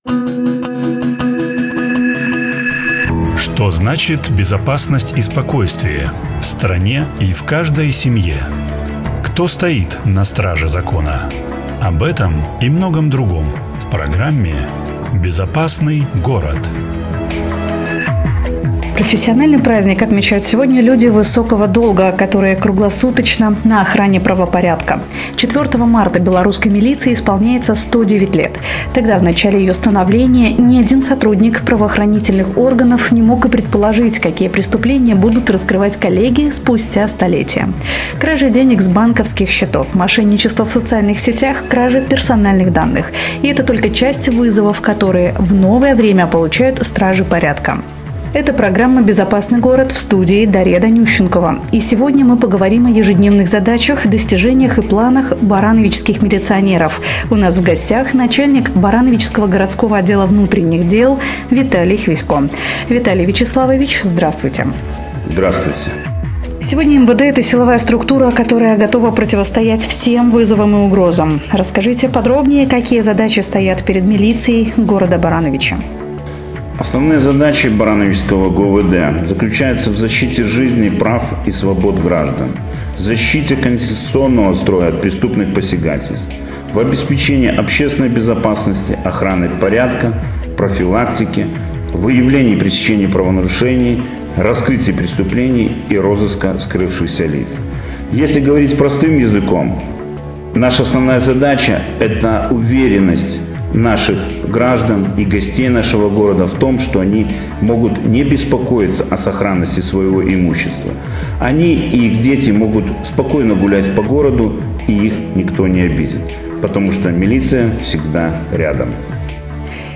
Tagged as гости студии